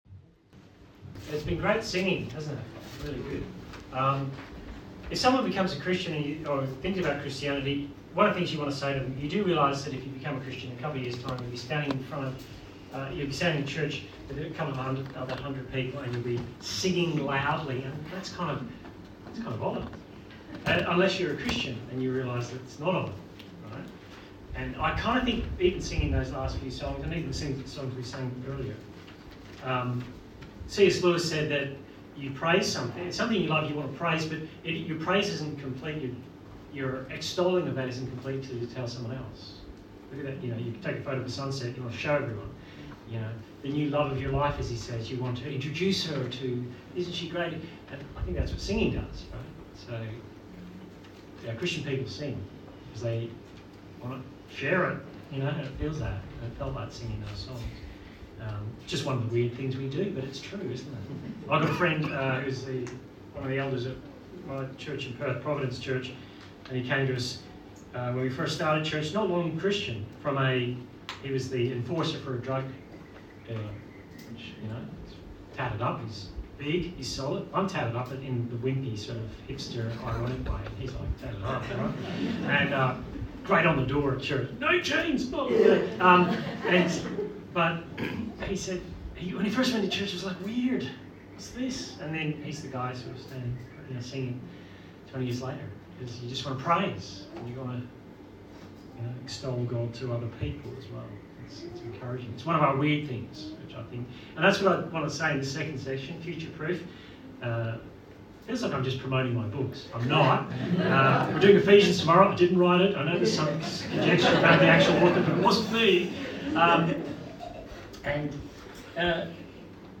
Service Type: Church Camp Talk